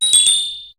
Cri d' Éoko dans Pokémon HOME .